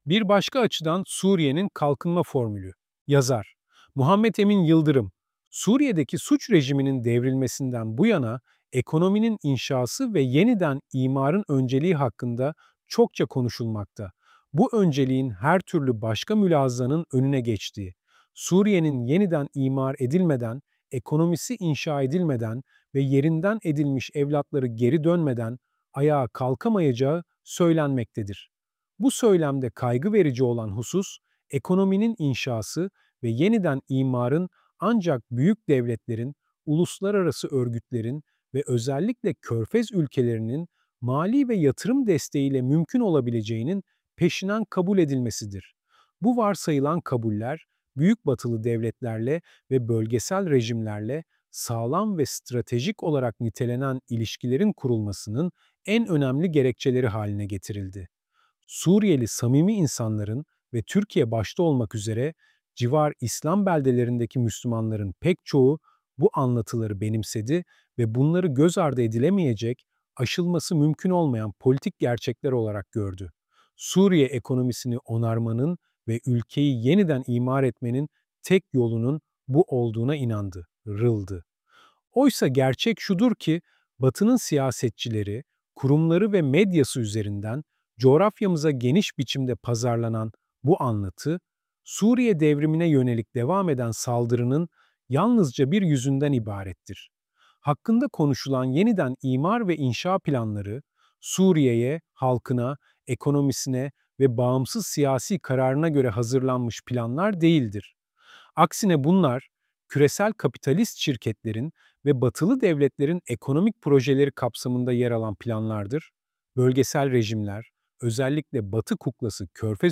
Bu ses yapay zeka tarafından oluşturulmuştur